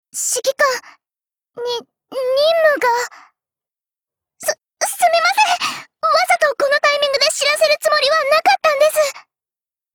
贡献 ） 分类:碧蓝航线:雅努斯语音 您不可以覆盖此文件。